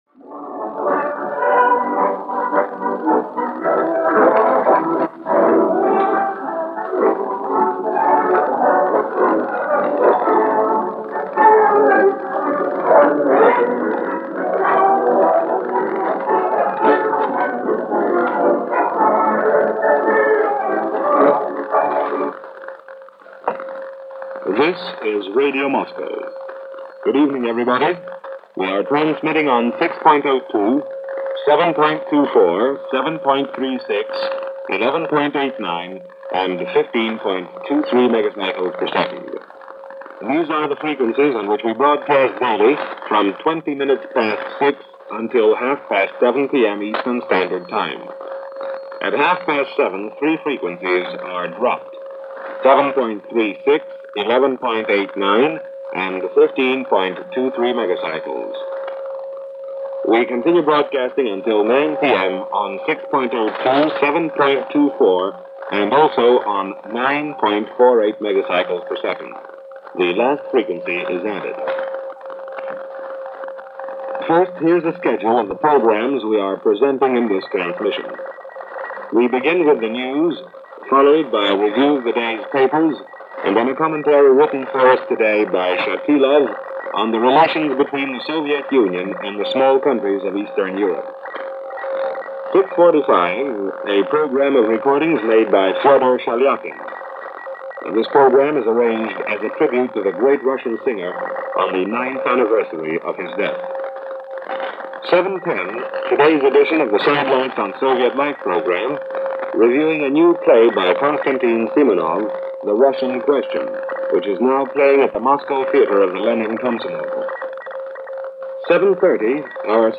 April 13, 1947 - Greetings Comrade! Radio Moscow during Cold War - A propaganda/news broadcast from Radio Moscow to North America, this day in 1947.